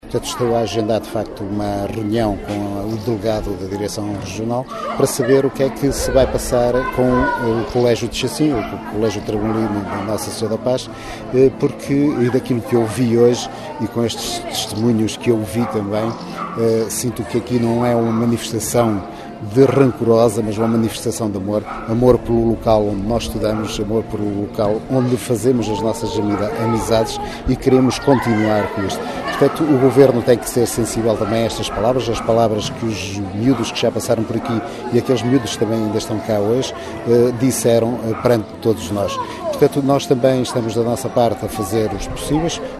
Duarte Moreno, o autarca local, também marcou presença. Reafirmou a intenção de agendar uma reunião com a tutela, e diz ainda que o Governo tem de ser sensível à situação do Colégio de Chacim.